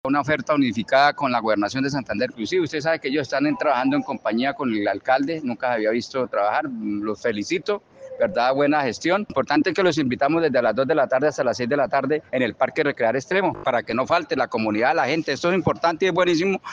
líder comunal